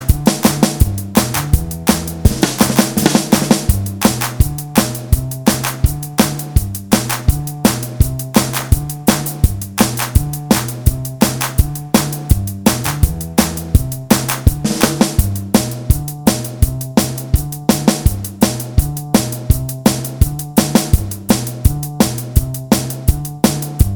No Guitars Pop (1960s) 2:17 Buy £1.50